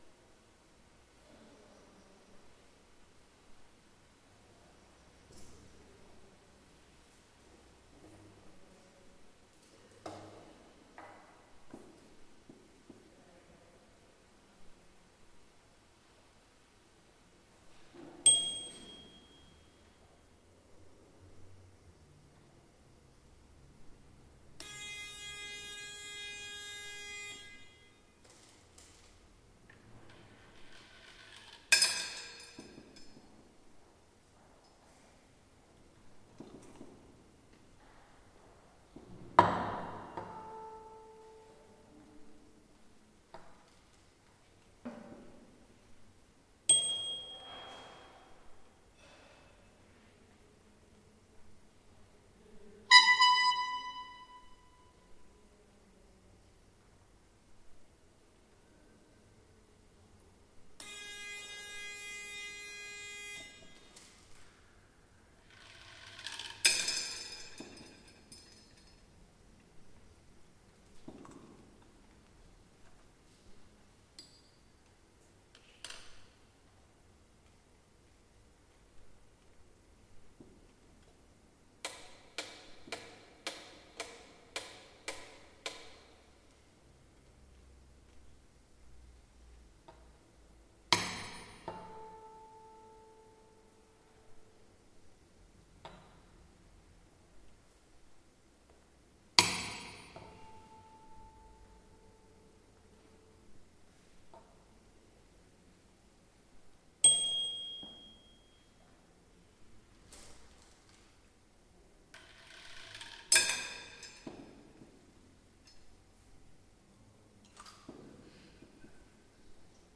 There were four pieces performed at the closing of the exhibition as well as the presentation of all of the work created by the team working in the gallery throughout the residency.
Each of the sounding objects is a sonic stimuli that was used to condition dogs in Pavlov's experiments: a struck bottle, tuning fork, horn, metronome, bubbling water, etc. Each of the symbols on the screen is a visual stimuli that was used to condition dogs in Pavlov's experiments.
Once the subject is seated, symbols will appear one at a time onto the screen in front of them, after which they must then use one of the sound-making objects.